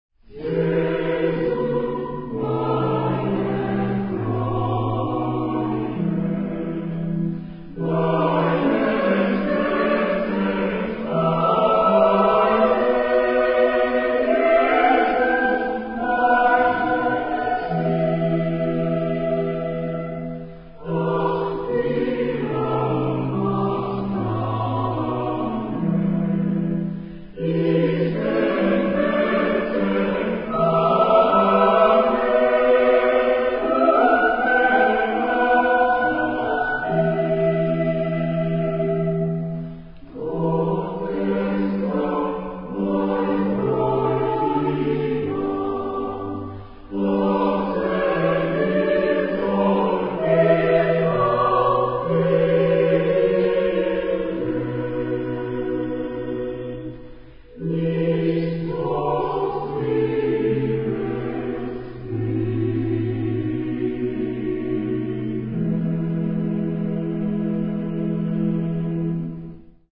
第38回定期演奏会　2010年10月17日（日）　　　撮影：スタッフ・テス
バッハ　モテット　イェスよ我が喜び 第38回定期演奏会　長野市ホクト文化ホール
オルガン